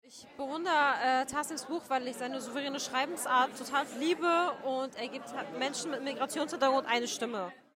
Das sagt auch eine Zuschauerin am Abend in Hamburg:
Publikum_Lesung.mp3